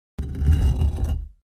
6.添加移动水缸音效
挪动水缸.wav